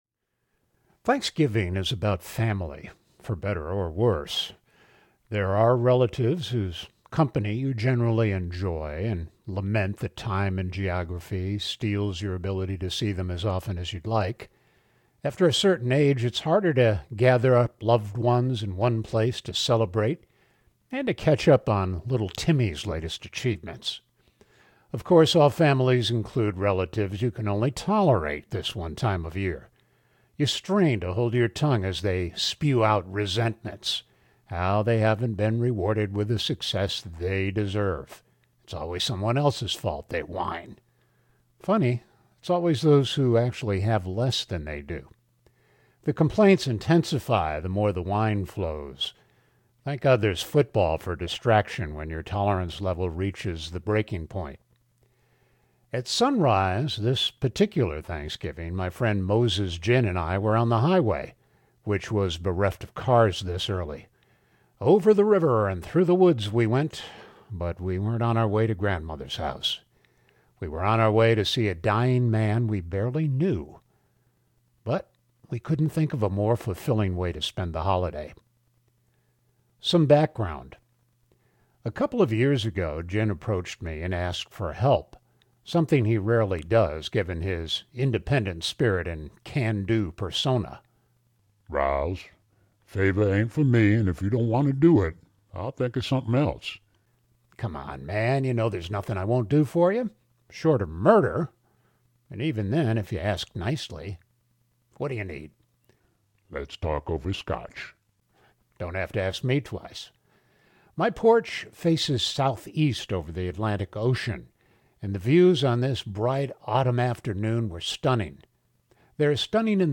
Jackson’s Book Audiobook